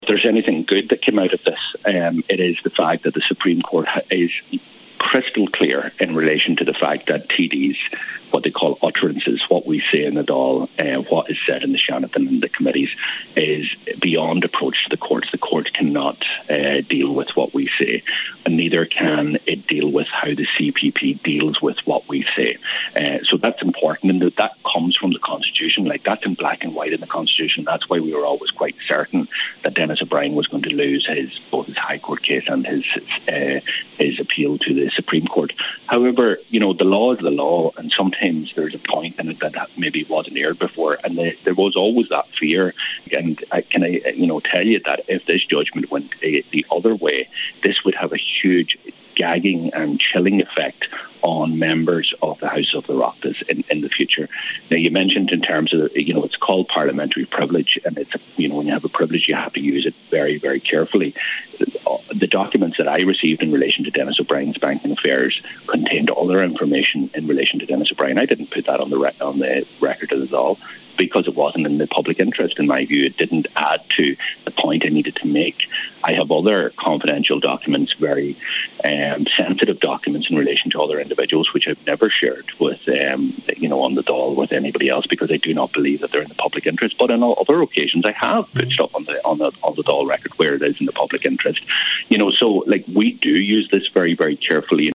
Deputy Pearse Doherty has been giving his reaction to the decision on today’s Nine till Noon Show……..